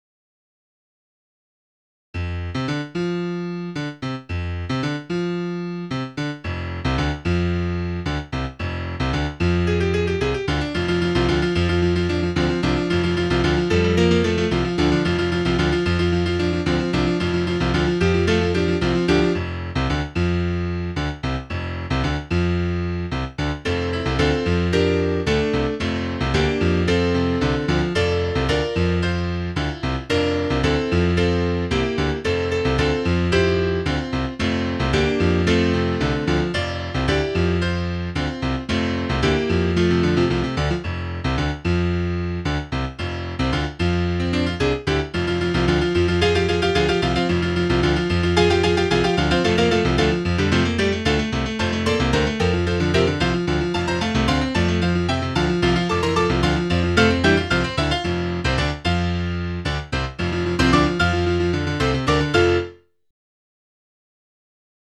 Title Flyin' Hi Opus # 11 Year 1990 Duration 00:01:05 Self-Rating 4 Description Something I wrote in high school, on an Apple II GS. mp3 download wav download Files: wav mp3 Tags: Solo, Piano Plays: 1728 Likes: 0